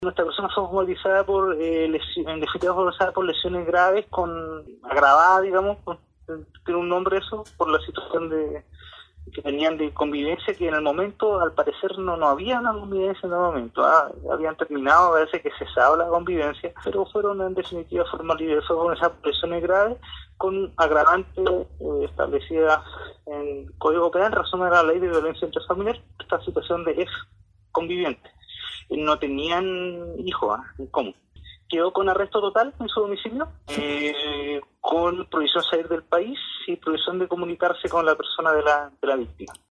El fiscal del ministerio público, Javier Calisto, expresó que el contexto de este episodio es un agravante para la causa que se persigue contra la agresora.